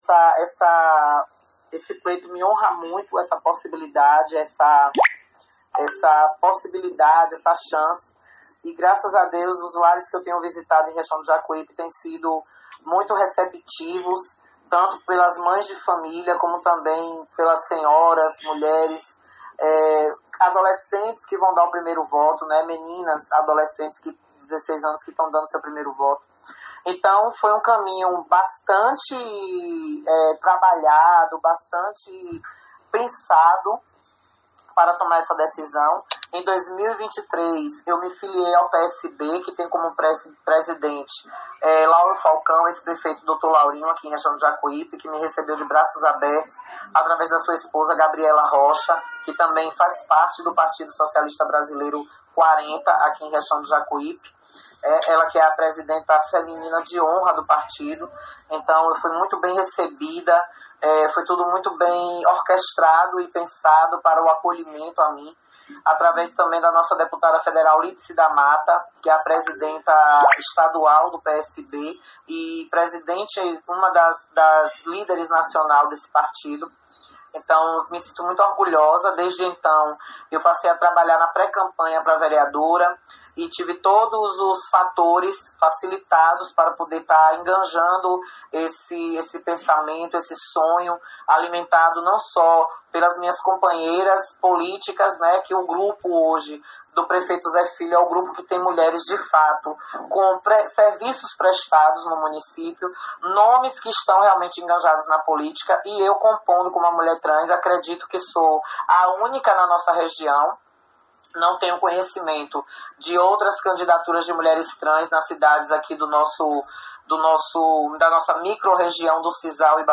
Ela conversou com a reportagem do CN e destacamos alguns pontos de entrevista.